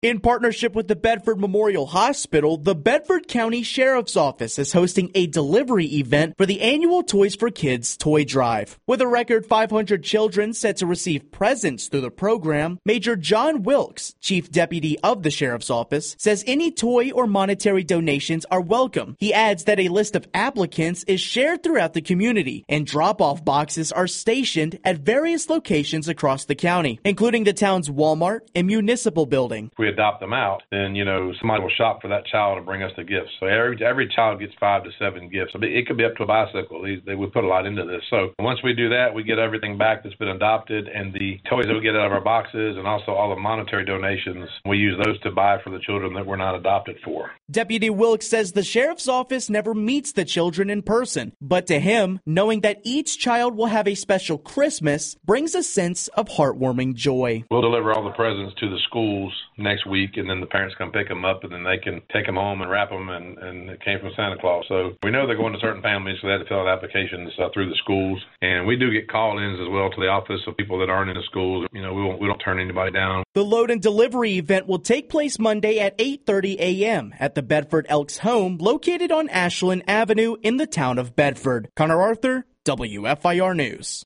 The post Bedford County Sheriff’s Office prepares for record-breaking Toys for Kids drive first appeared on News/Talk 960-AM & FM-107.3 WFIR.